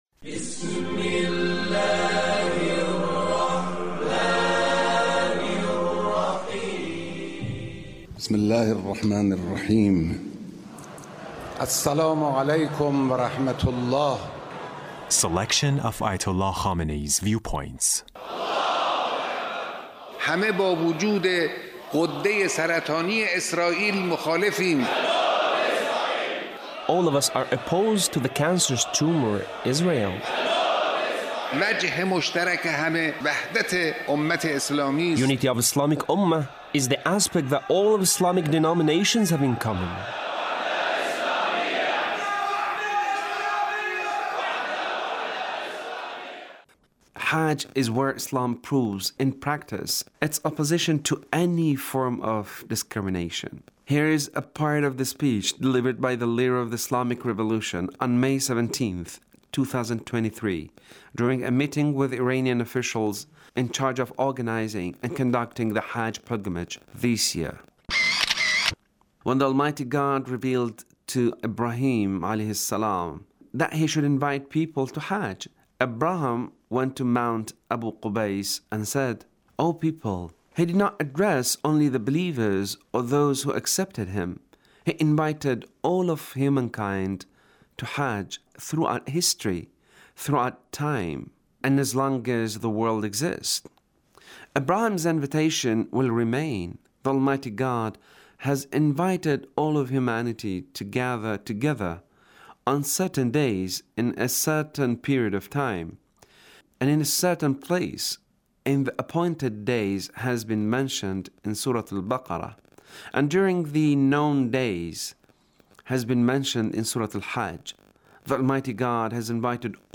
Leader's Speech in A Meeting with Iranian Commanders and Senior Military Officials